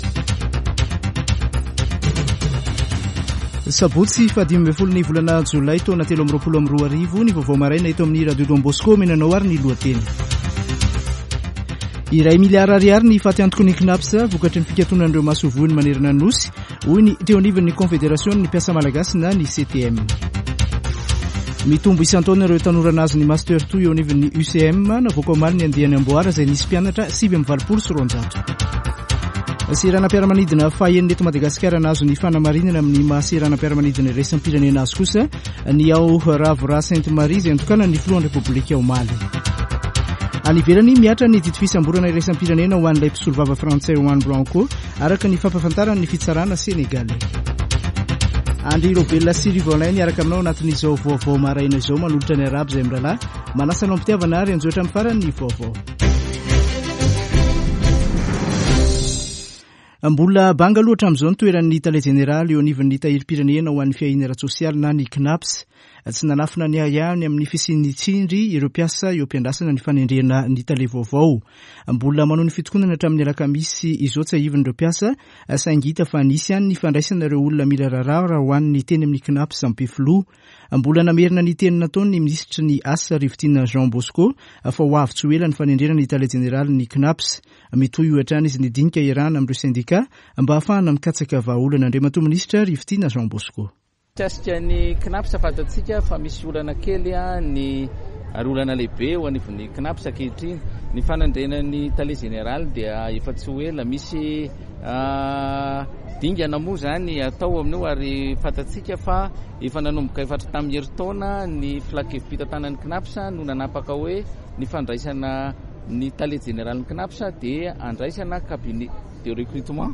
[Vaovao maraina] Sabotsy 15 jolay 2023